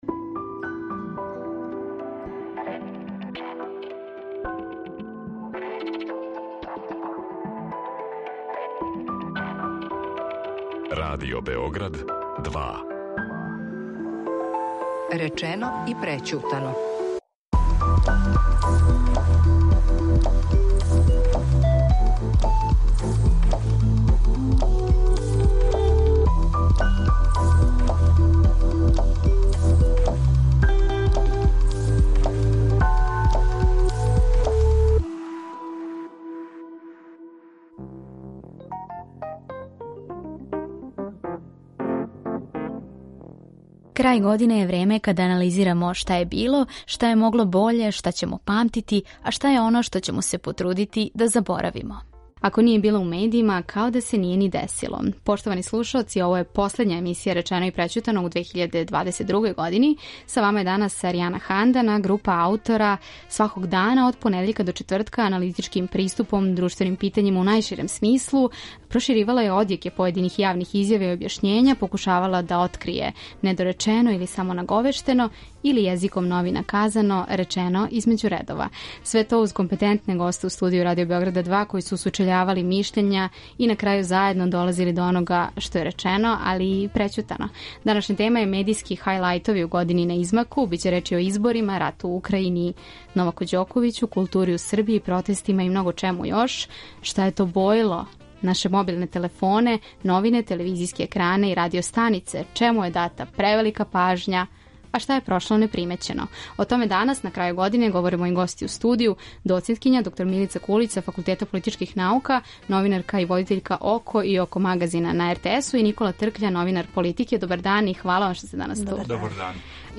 Чему је дата превелика пажња, а шта је прошло непримећено? О томе данас, на крају године, говоре гости у студију